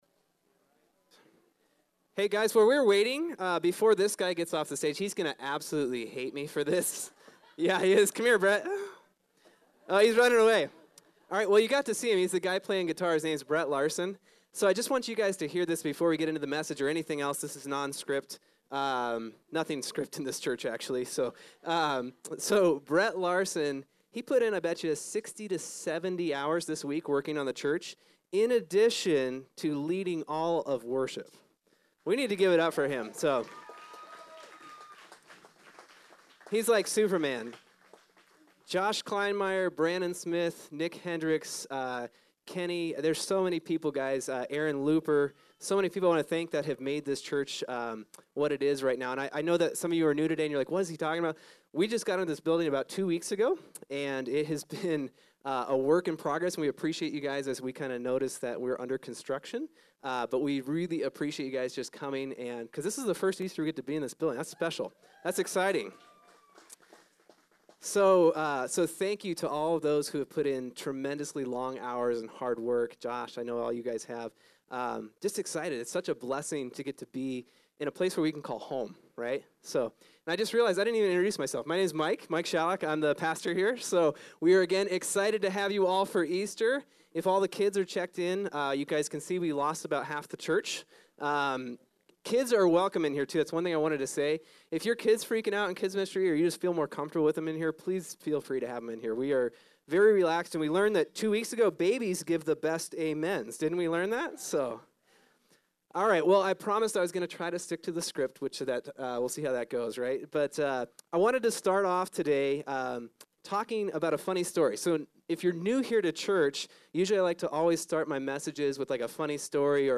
Easter 2019